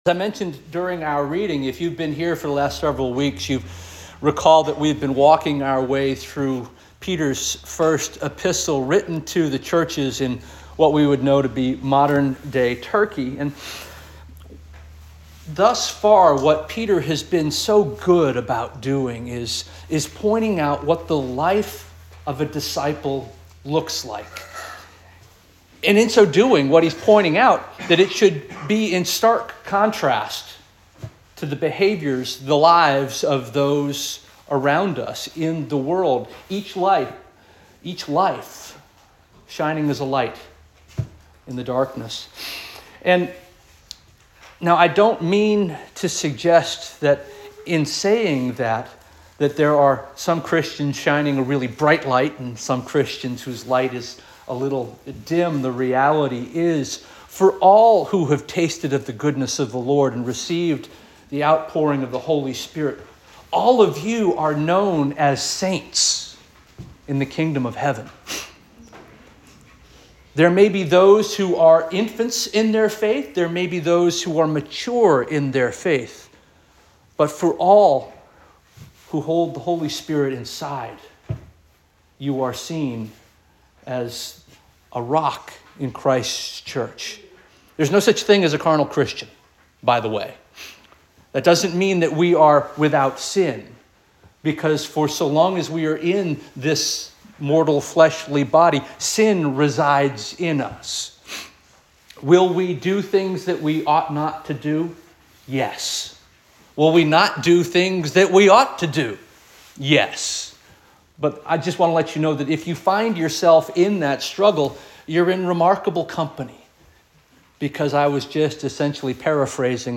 March 2 2025 Sermon